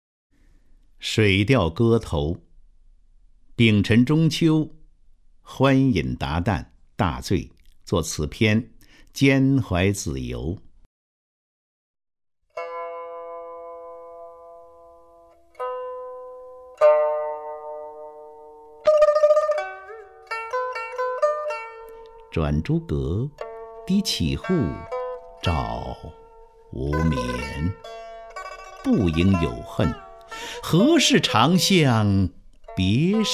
张家声朗诵：《水调歌头·明月几时有》(（北宋）苏轼)　/ （北宋）苏轼
ShuiDiaoGeTouMingYueJiShiYou_SuShi(ZhangJiaSheng).mp3